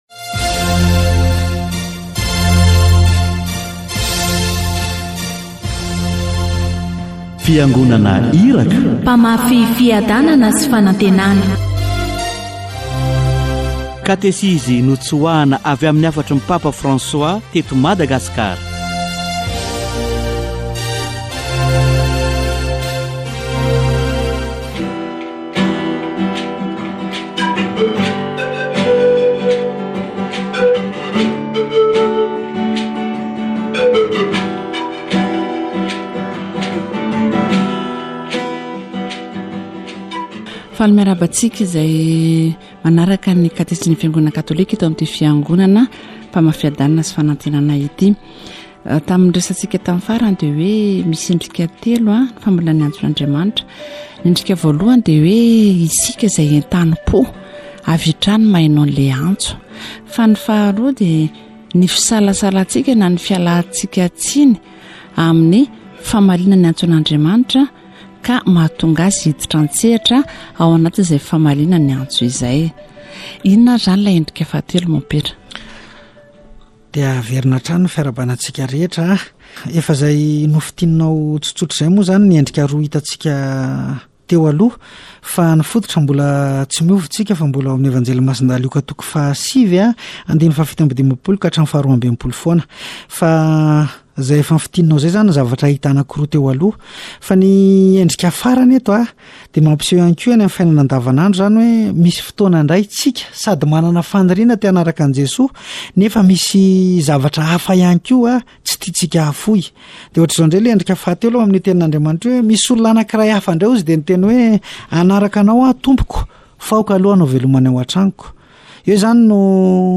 Catechesis on attentive listening to the call